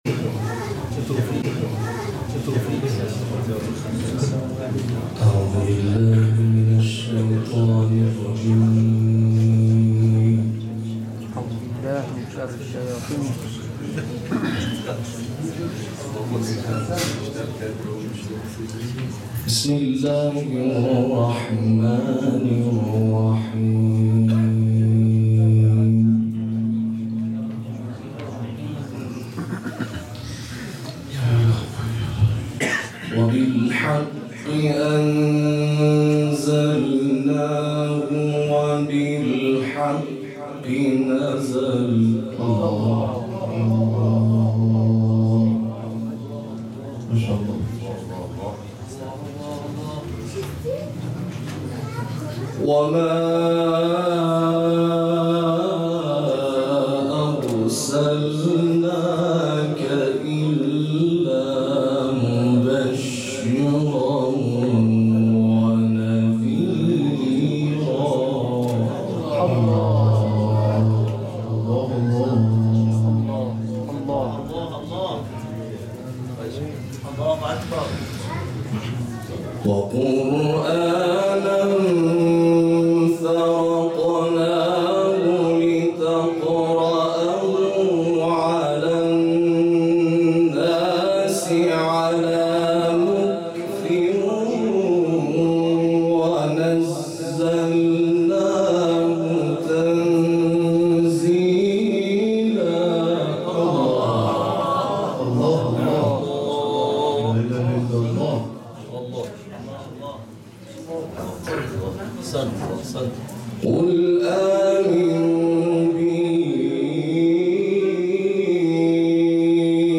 قاری بین‌المللی کشورمان در محفل قرآنی منادی با اشاره به اینکه قاری قرآن بایستی بتواند بر اساس توانایی‌ها و داشته‌های خود، بهترین عملکرد را ارائه دهد، گفت: فهم آموزه‌های قرآنی مانند خانه‌ای است که کلید آن، تلاوت قرآن است.